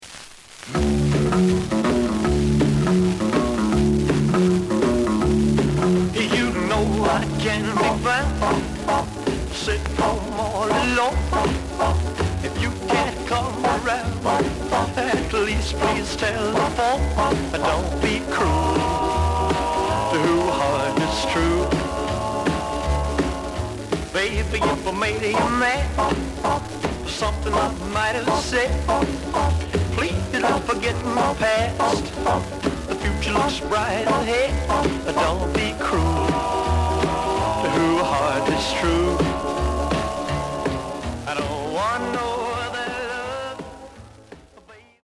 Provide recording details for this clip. The audio sample is recorded from the actual item. Some noise on both sides.